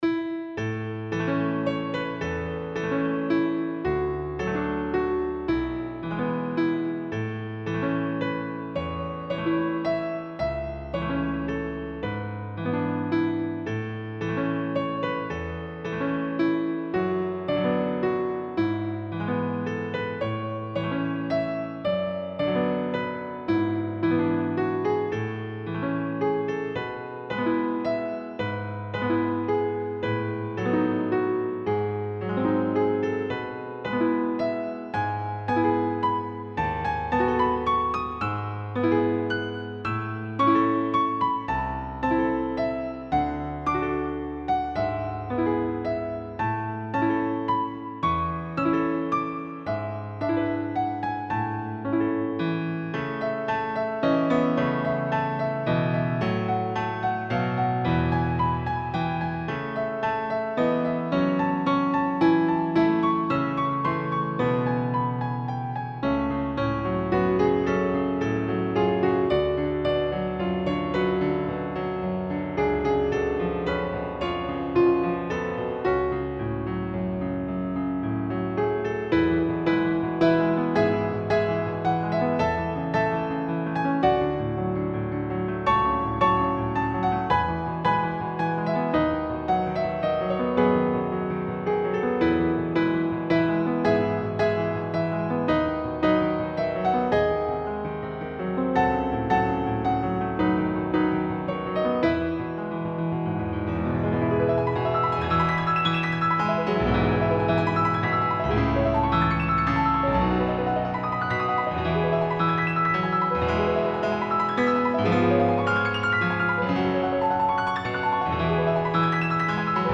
Op69 The Piano Arrangement of a Hymn
Hello everyone, I am back, this time I'm going to post a piano arrangement of a Christian hymn, hope you like it!